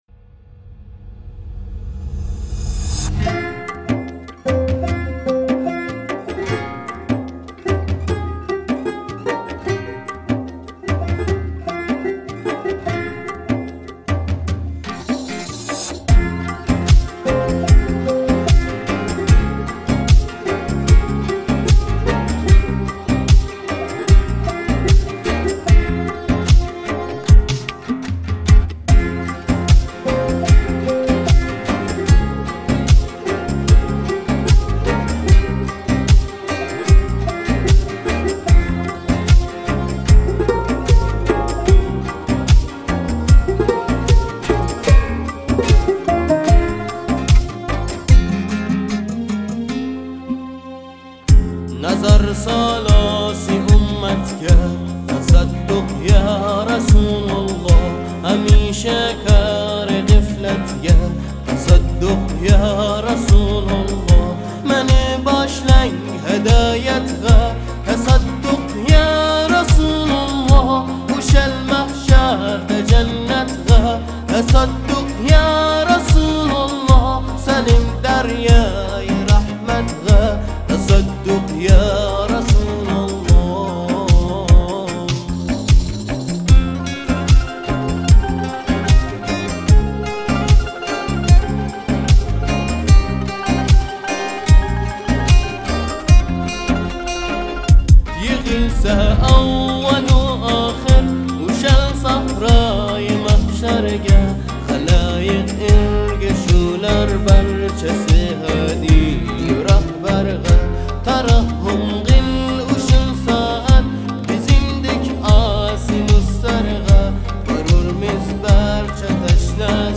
Ҳазиний ғазали.